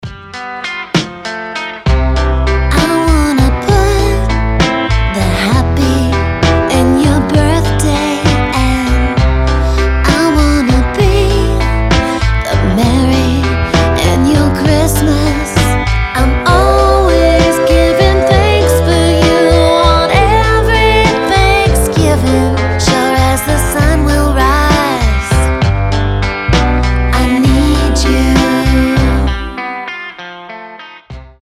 • Качество: 320, Stereo
поп
гитара
женский вокал
нежные
трогательные